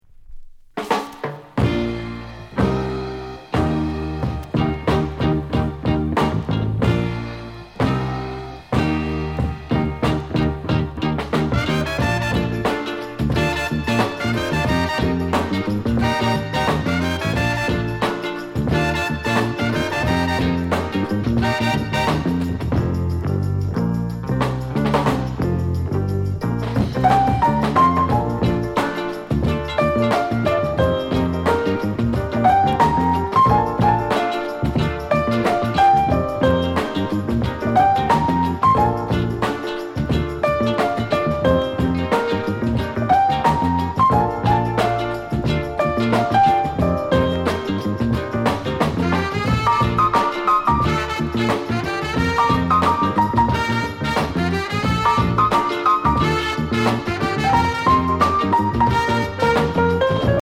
GREAT INST